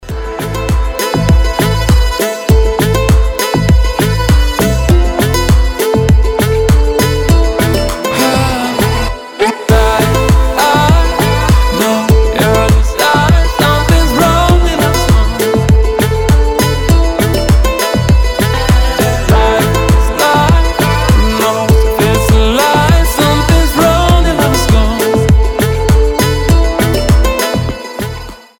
• Качество: 320, Stereo
гитара
мужской вокал
красивые
deep house
восточные мотивы
грустные